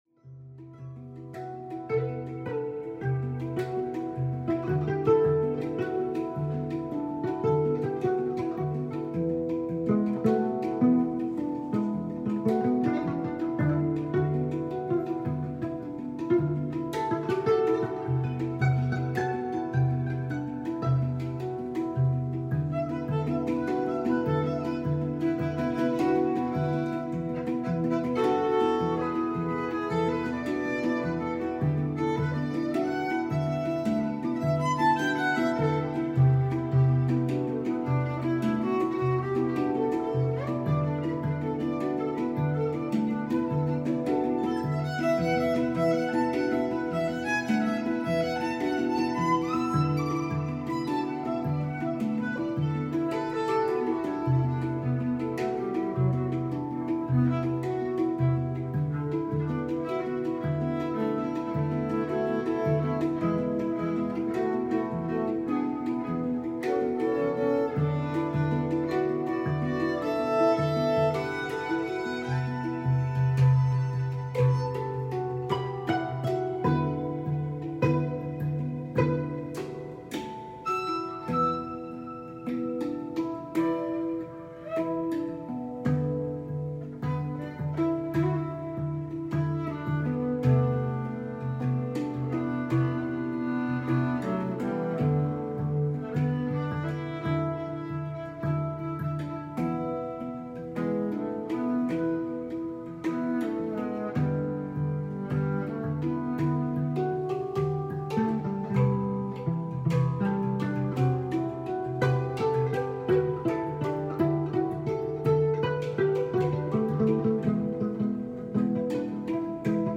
Healing handpan & violin music sound effects free download
handpan & violin
Healing handpan & violin music at our local hospital. 🛸🎶🎻❤🙌🏥❤‍🩹 Live 5 min improv